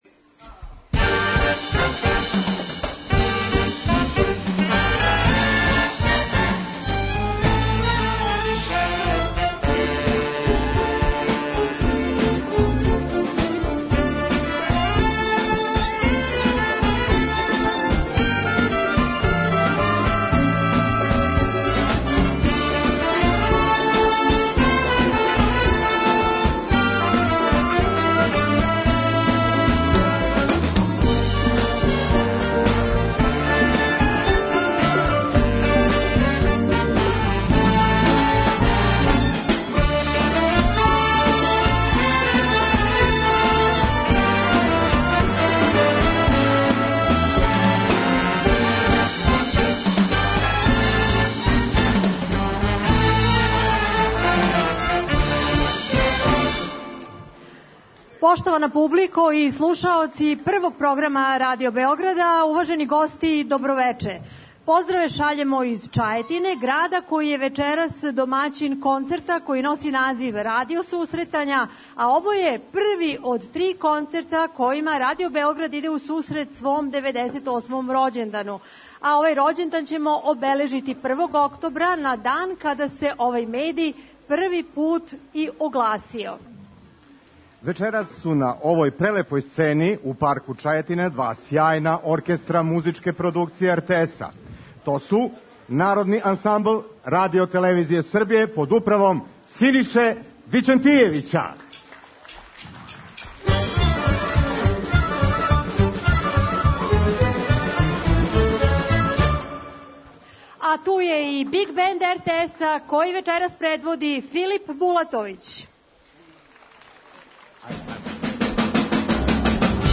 Radio susretanja, direktan prenos koncerta iz Čajetine
Oni će izvoditi šlagere, narodnu, pop i zabavnu muziku.
Ideja je da se vokalni solisti, kojji su karijeru izgradili u jednom muzičkom žanru, pokažu i kao vrsni izvođači i u nekom drugom žanru.